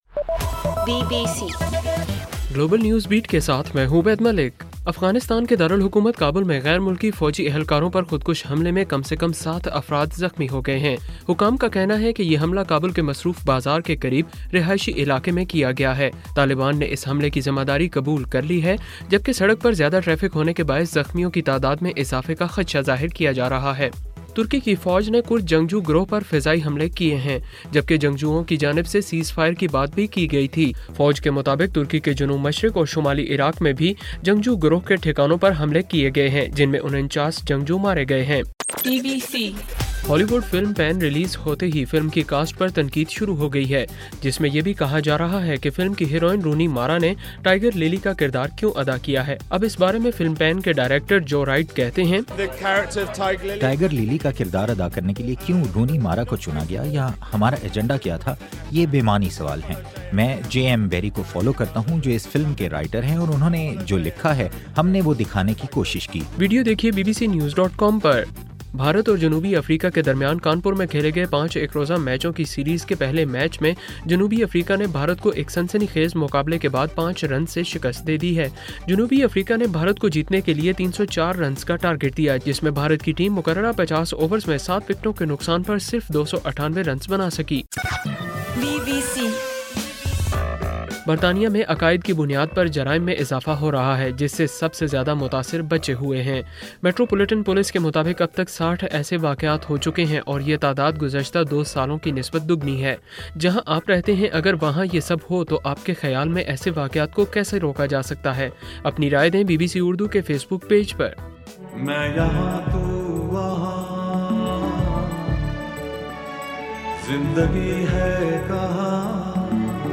اکتوبر 11: رات 10 بجے کا گلوبل نیوز بیٹ بُلیٹن